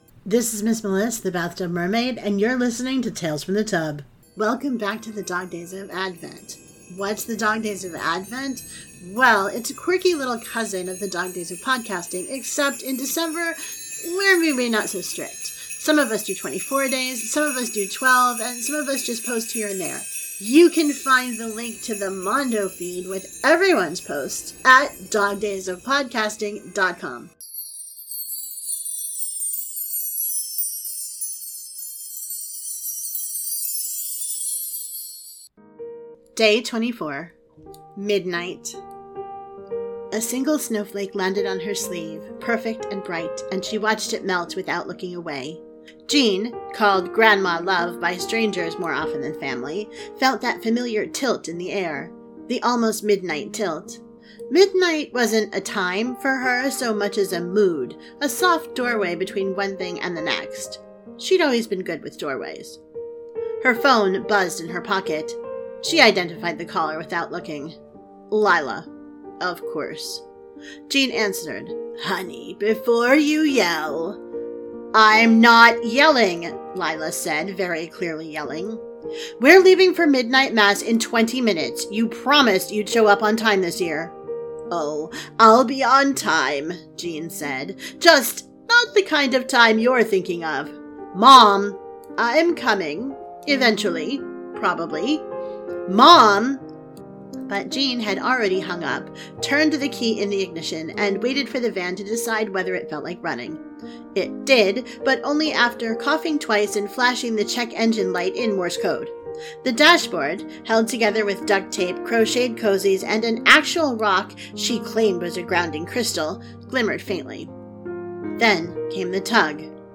• Sound Effects and Music are from Freesound and UppBeat